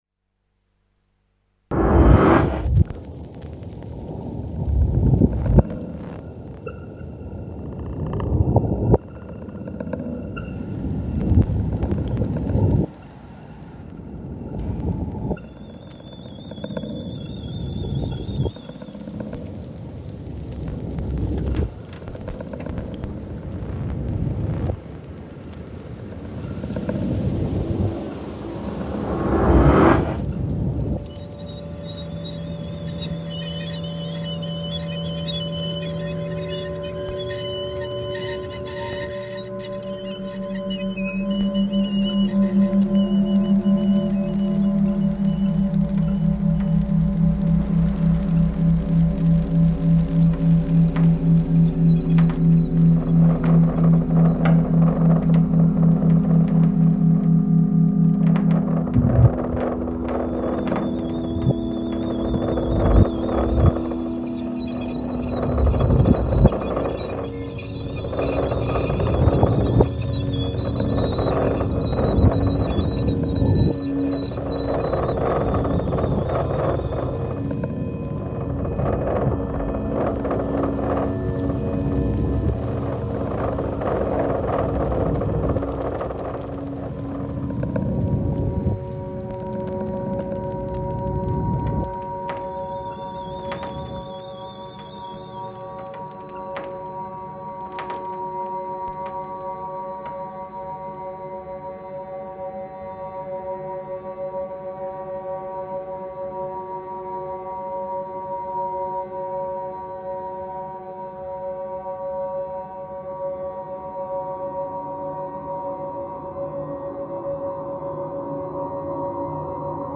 patiently unfolding electro-acoustic constructions